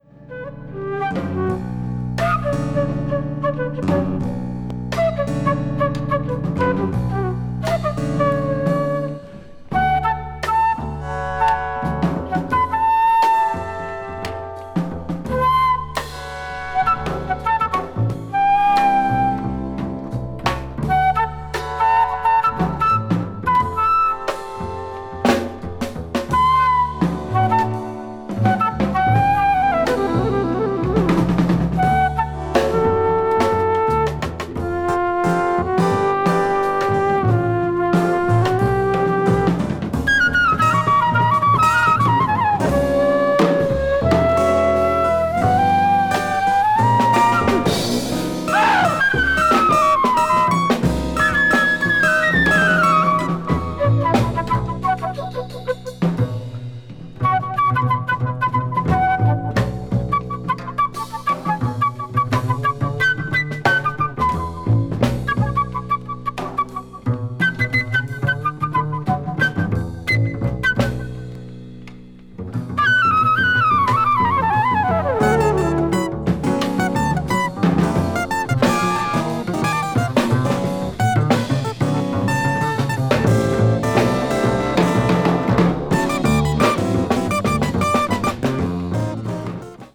piano
bass
avant-jazz   contemporary jazz   ethnic jazz   free jazz